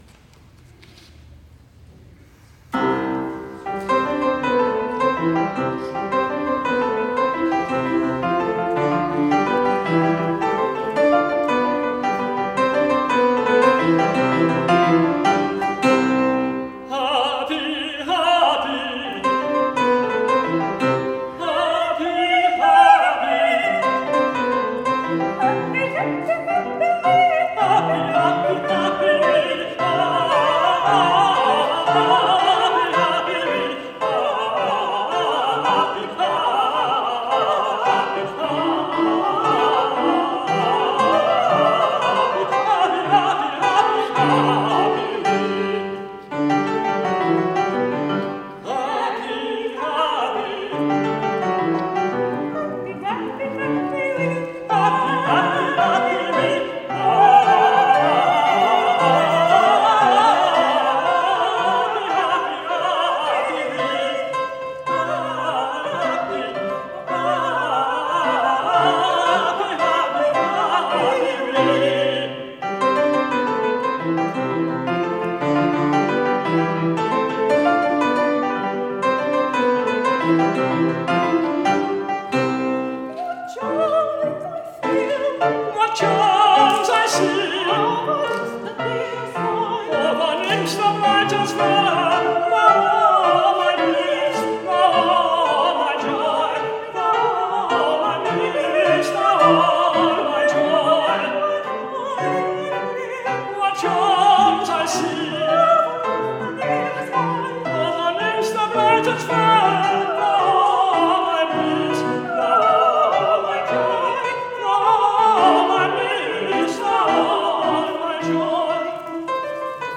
DUETS:
soprano
tenor
piano - live in concert 2023